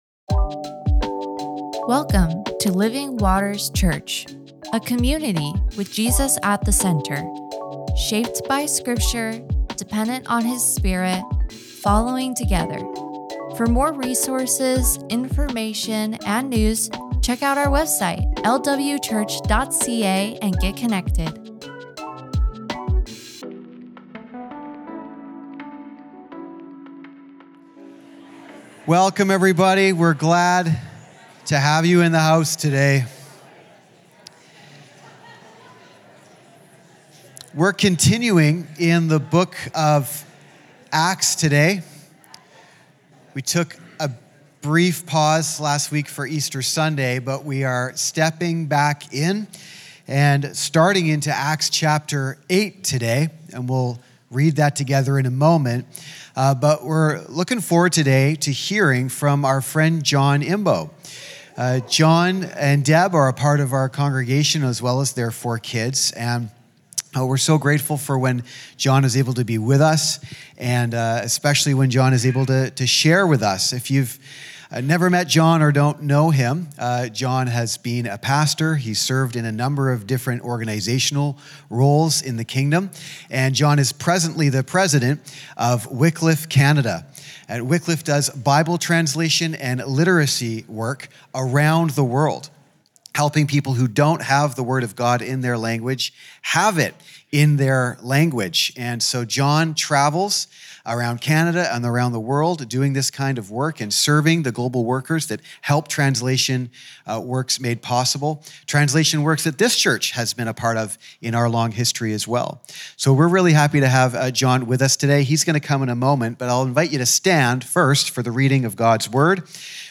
Sermons | Living Waters Church